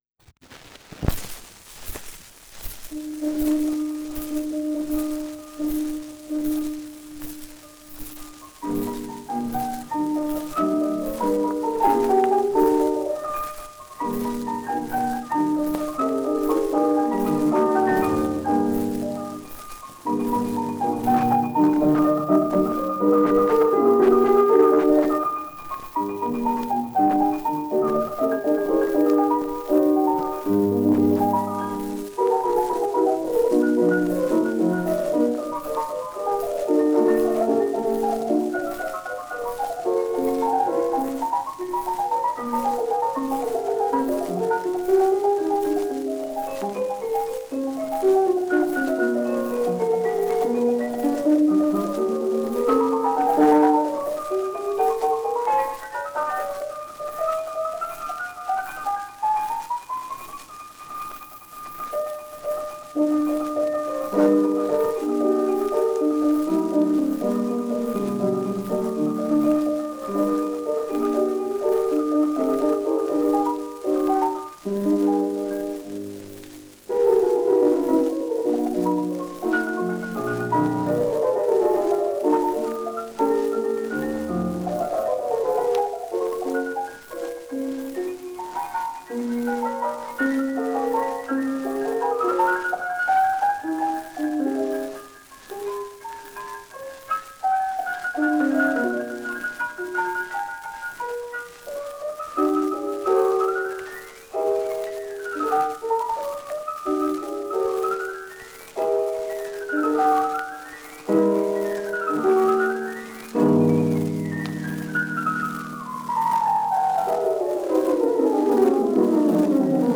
forgotten pianist
Basic denoise of da campy in Izotope, not done with much subtlety, has reduced the volume somewhat but there is a fair bit of hiss to deal with. Could probably remove a lot of the clicks manually but that is quite time-consuming.